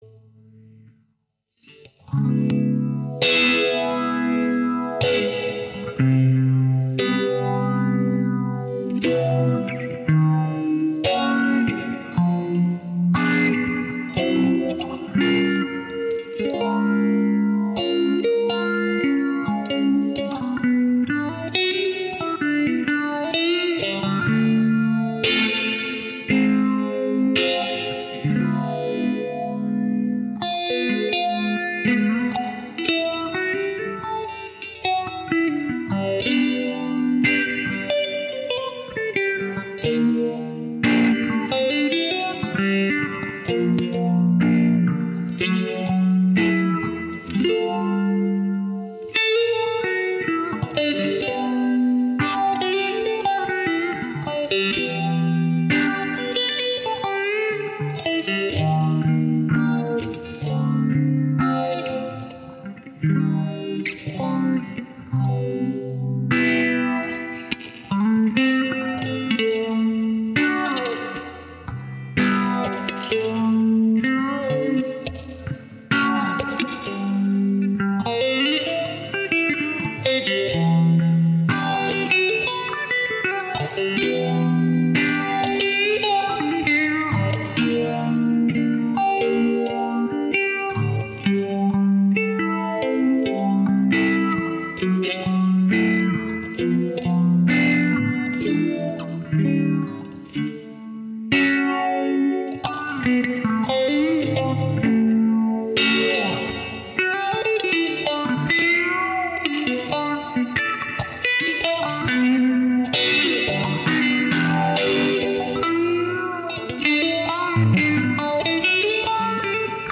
描述：一个未完成的奇怪和愚蠢的音乐曲目与强烈的rhytm和一些电子吉他独奏:)